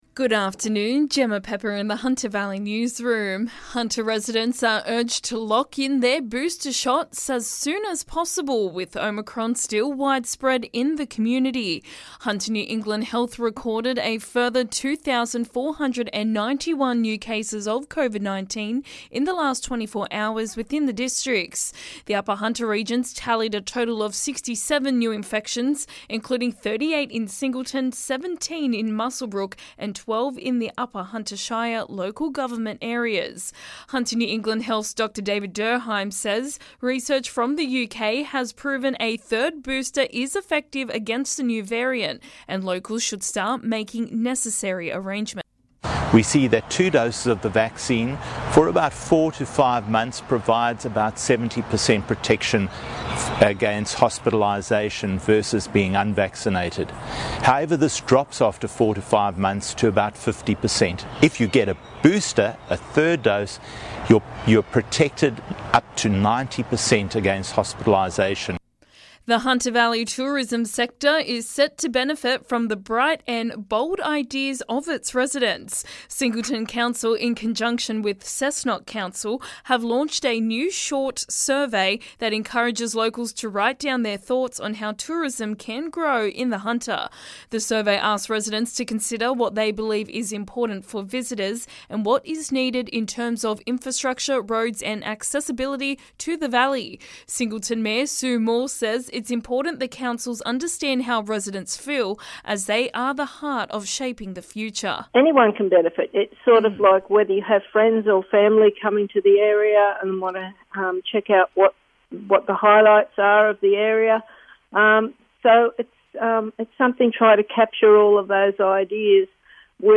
LISTEN: Hunter Valley Local News Headlines 13/01/22